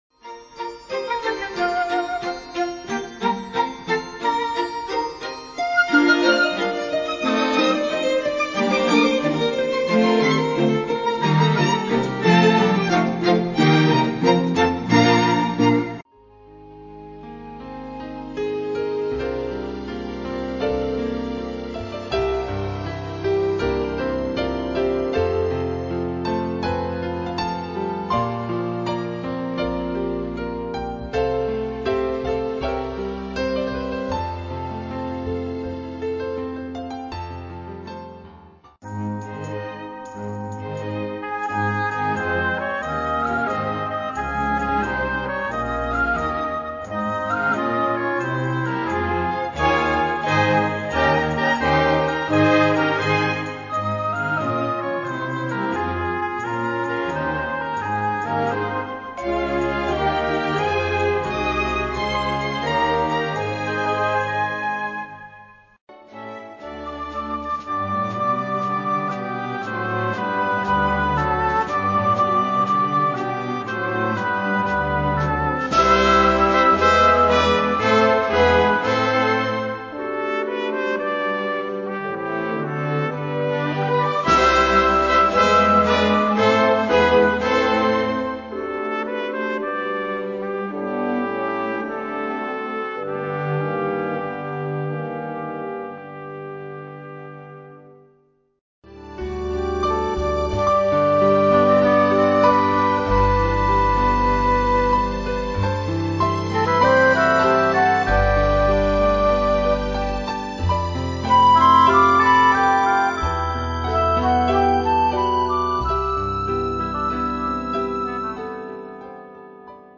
sparkling instrumentals
Traditional